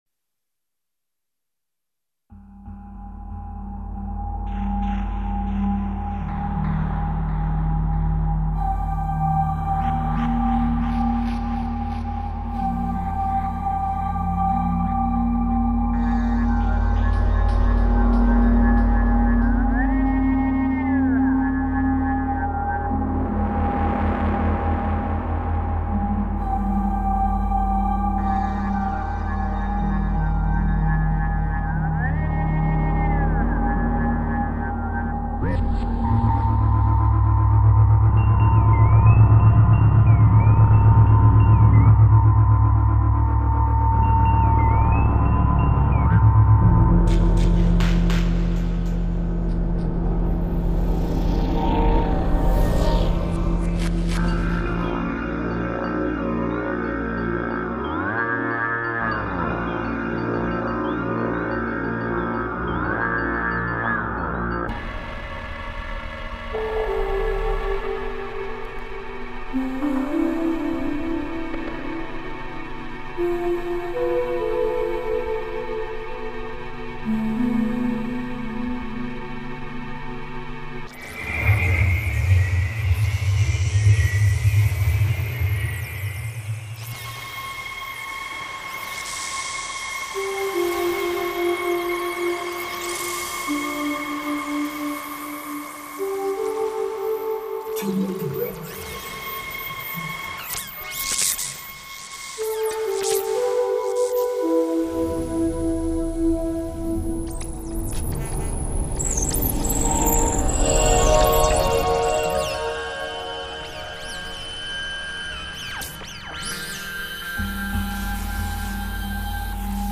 SOUNDTRACK MUSIC ; ELECTRONIC MUSIC